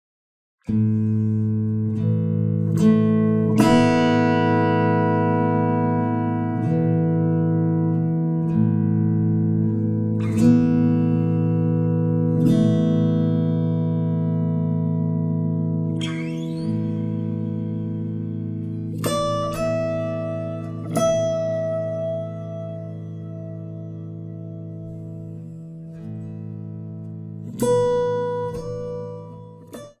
Original Guitar [3]
Guitar_Quantum_40.mp3